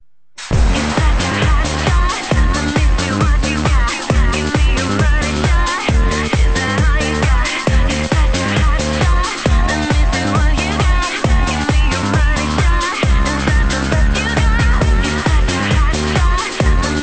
in Szczecin (Poland)